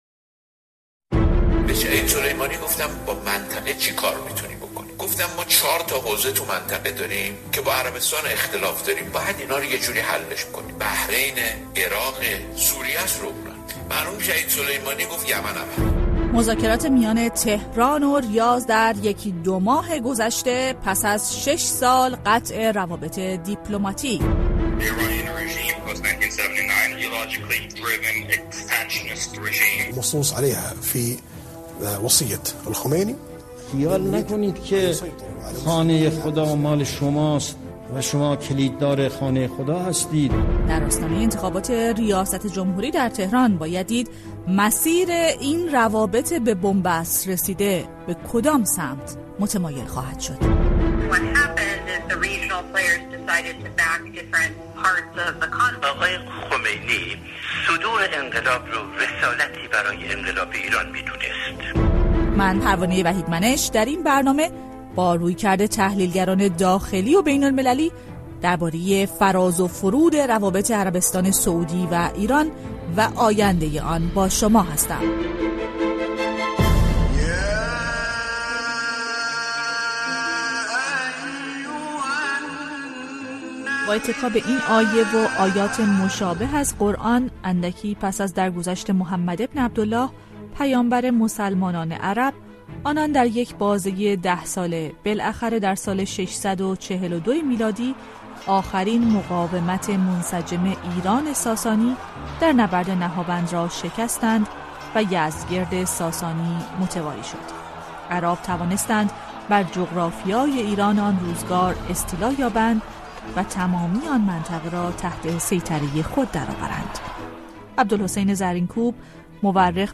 این مستند رادیویی، روایتی است از تاریخ صدسالهٔ روابط ایران و عربستان سعودی همراه با مصاحبه با چندین کارشناس ایرانی و غیرایرانی برای تحلیل چشم‌انداز روابط میان دو کشور است.